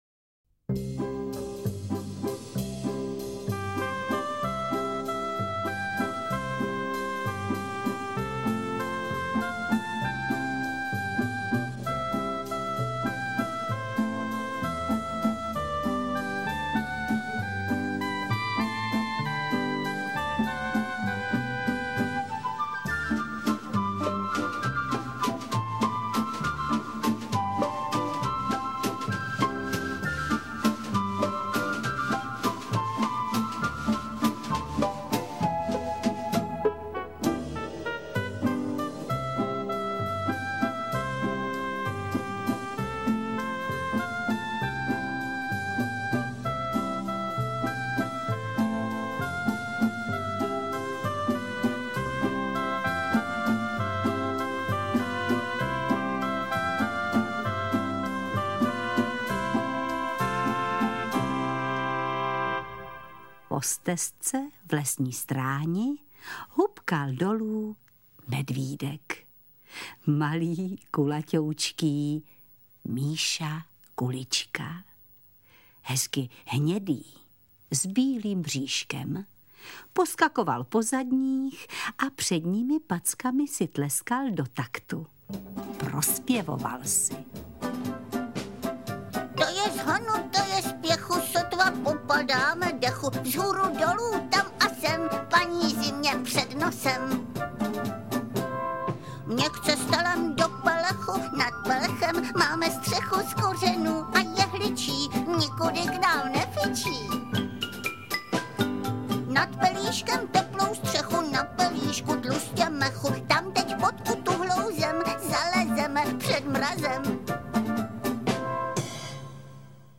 Interpret:  Helena Štáchová